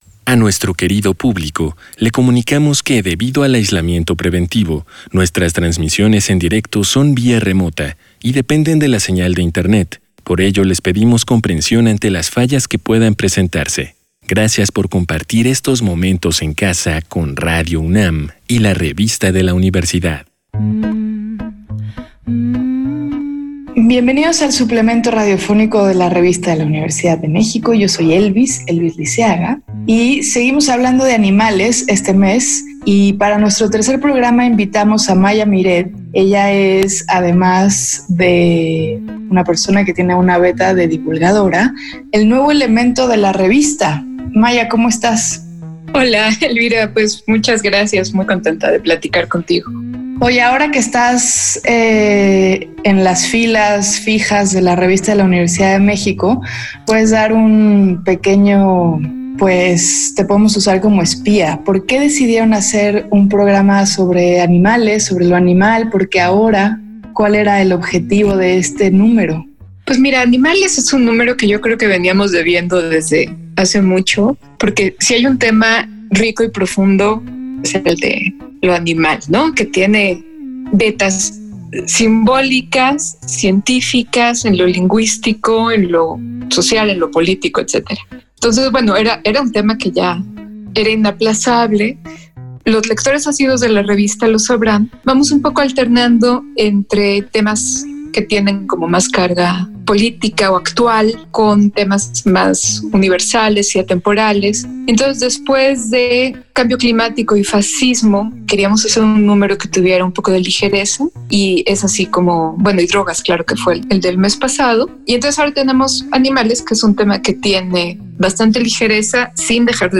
Fue transmitido el jueves 21 de mayo de 2020 por el 96.1 FM.